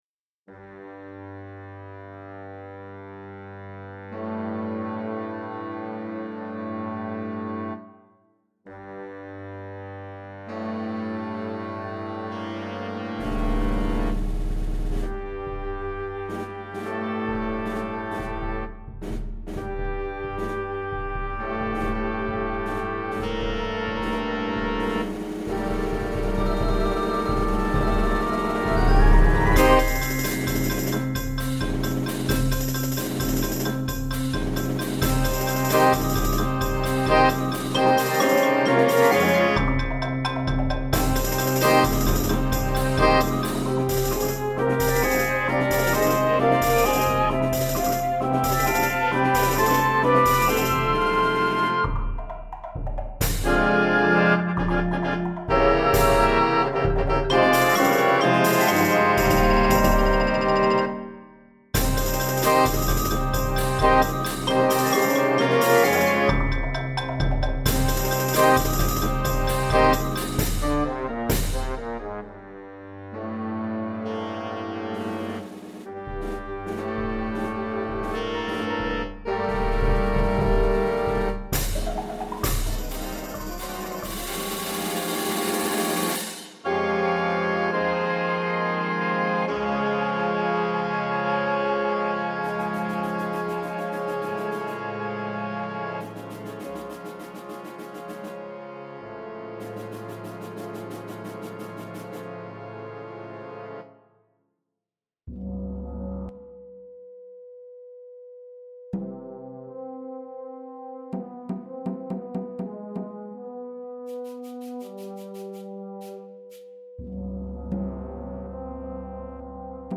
Piccolo
Flute 1, 2
Oboe 1, 2
Clarinet 1, 2, 3
Alto Sax 1, 2
Trumpet 1, 2, 3
French Horn 1, 2
Trombone 1, 2
Euphonium
Tuba
Snare Drum
Bass Drum